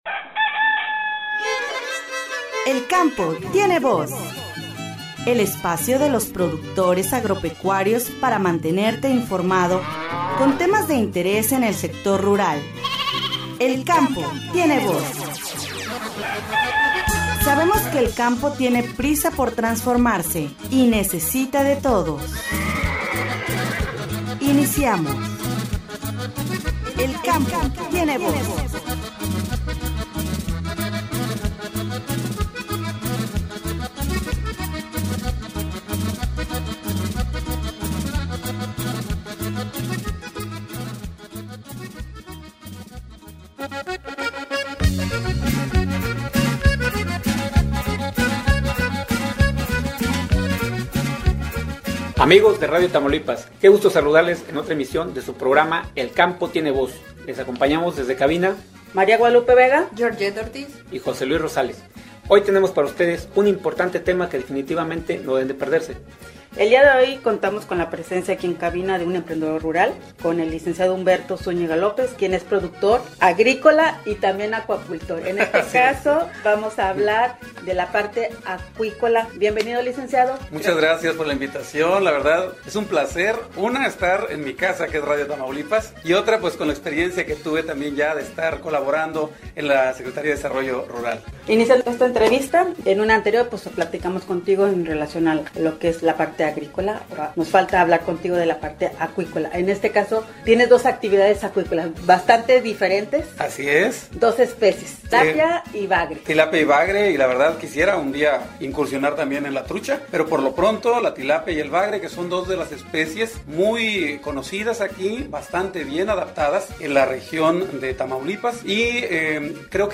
“El Campo Tiene Voz”, tuvo el honor de entrevistar al productor rural, Lic.